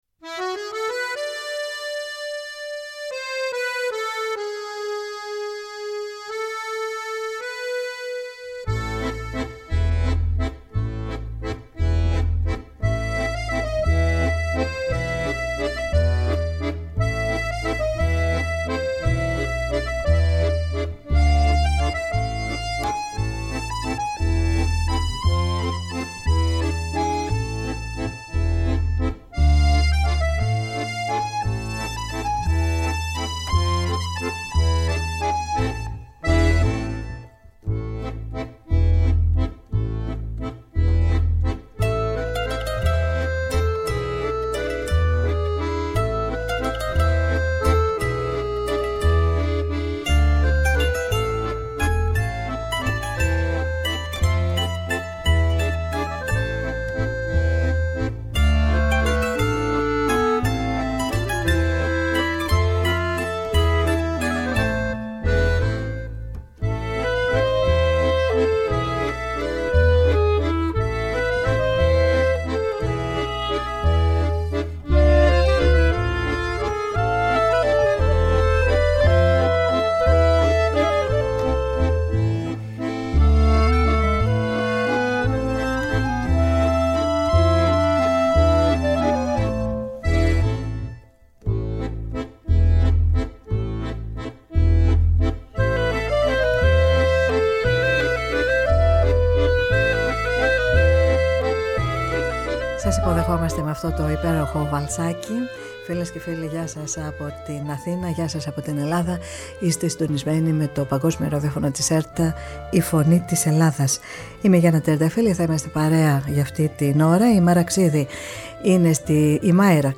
στο Studio της Φωνής της Ελλάδας.